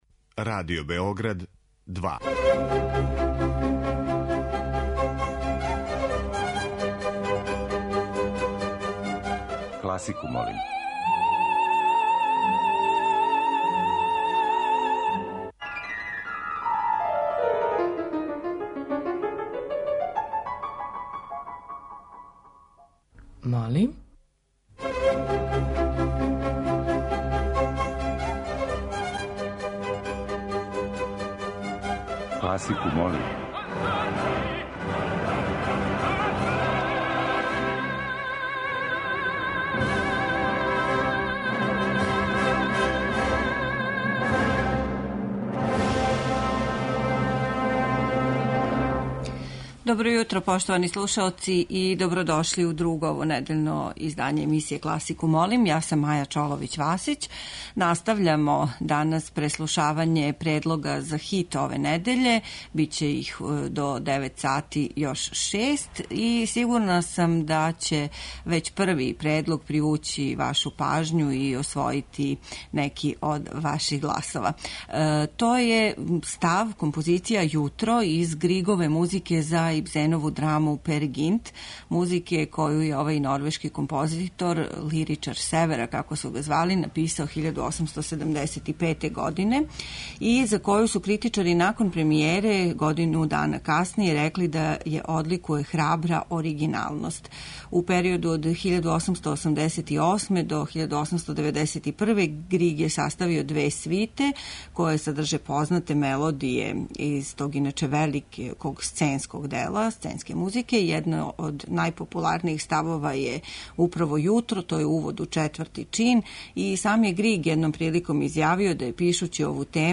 Уживо вођена емисија, окренута широком кругу љубитеља музике, разноврсног је садржаја. Подједнако су заступљени сви музички стилови, епохе и жанрови.